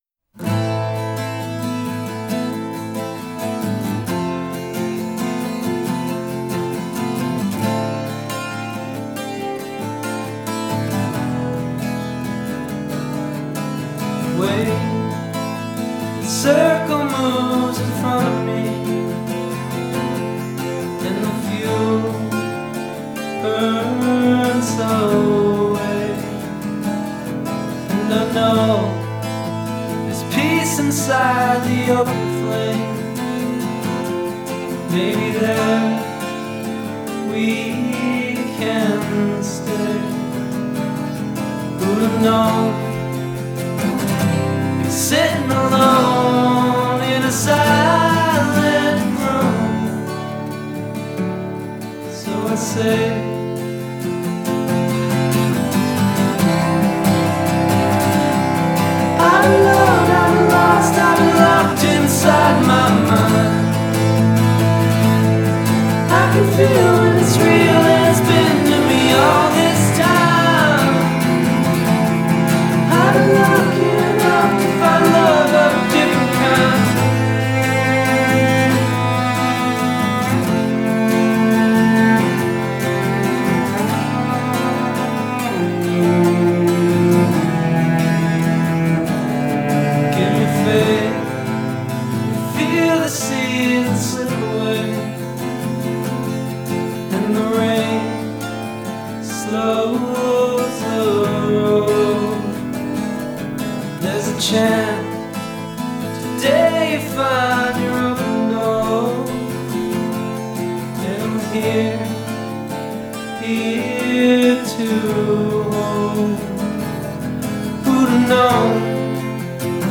la guitare acoustique et l’orchestration